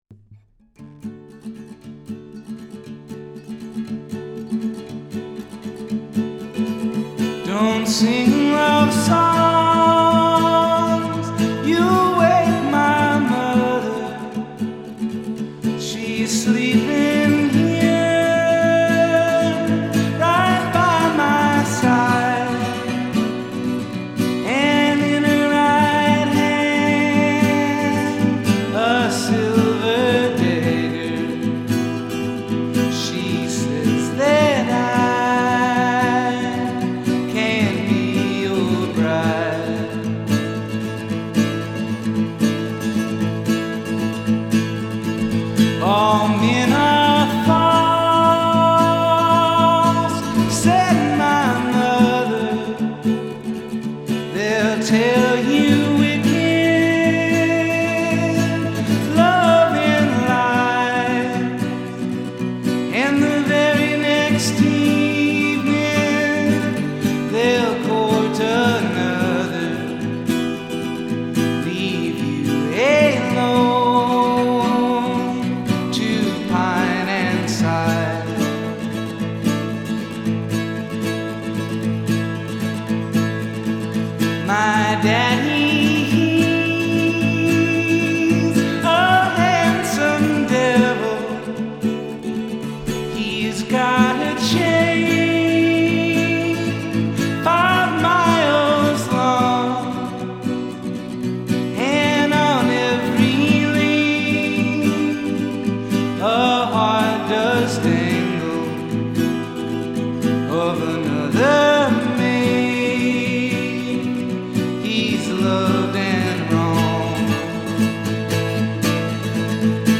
Cover version
One man, his delicately skipping guitar and a perfectly in tune reverb-soaked vocal.